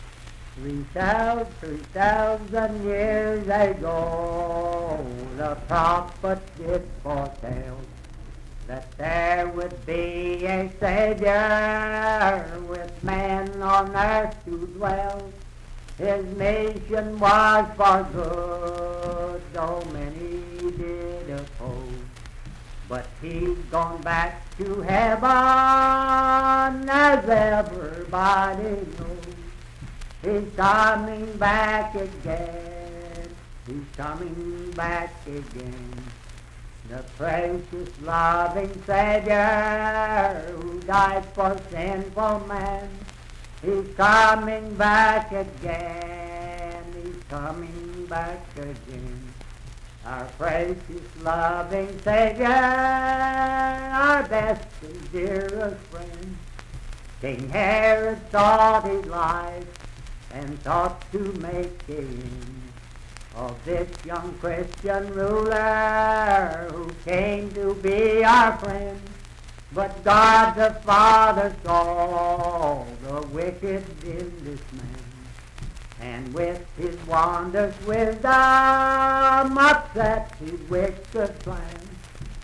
Unaccompanied vocal music and folktales
Verse-refrain 2(4) & R(4).
Hymns and Spiritual Music
Voice (sung)
Parkersburg (W. Va.), Wood County (W. Va.)